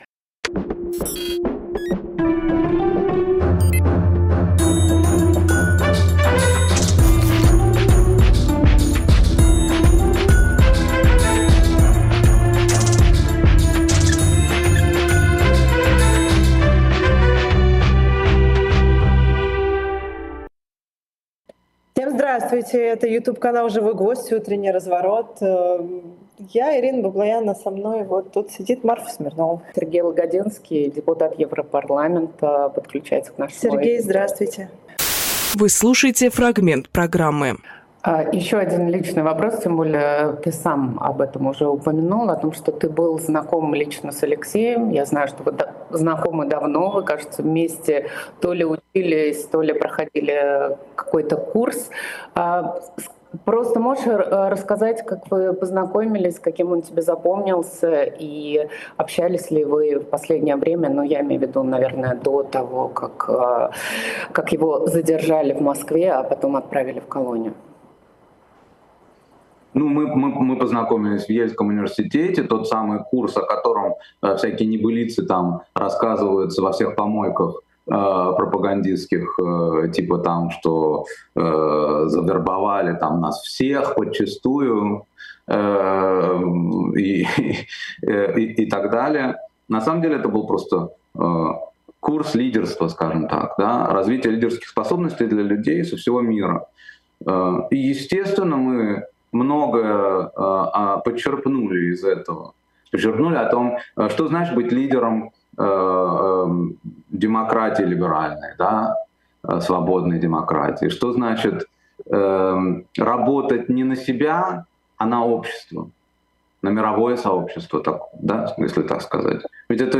Фрагмент эфира от 01.03